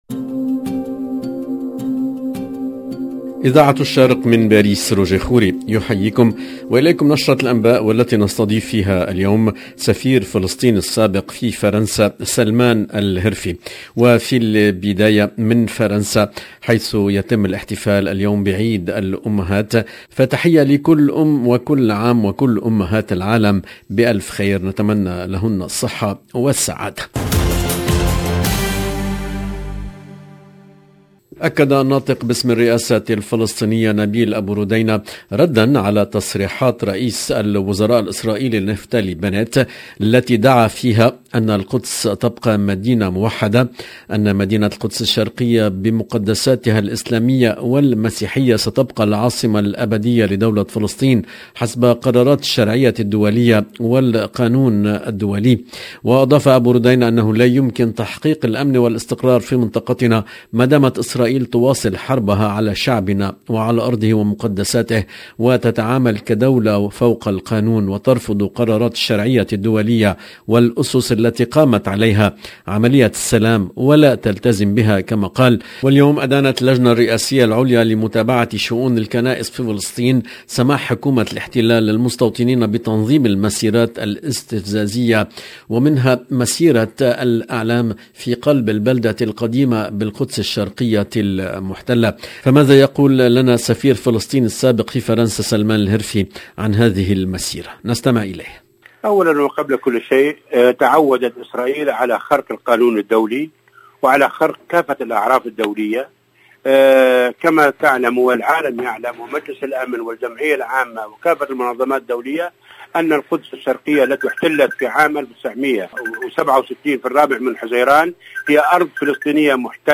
LE JOURNAL DU SOIR EN LANGUE ARABE DU 29/5/2022